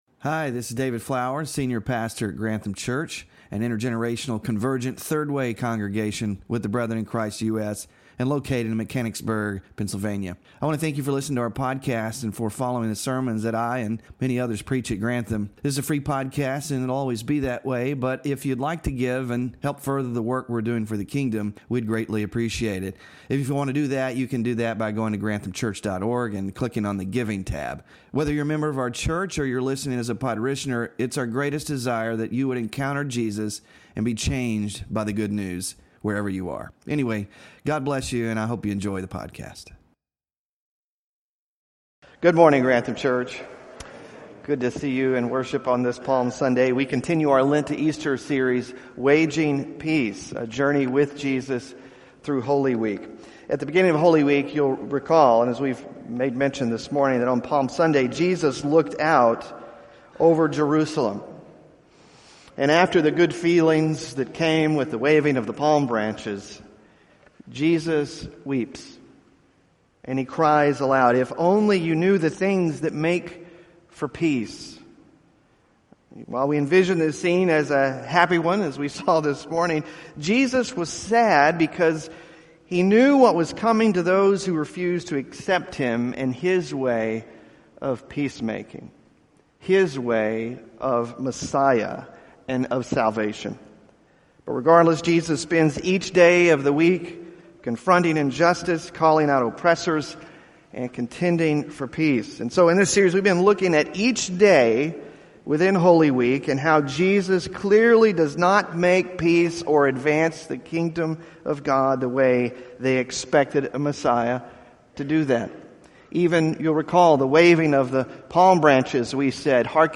WORSHIP RESOURCES Waging Peace (6 of 7) Sermon Slides Small Group Discussion Questions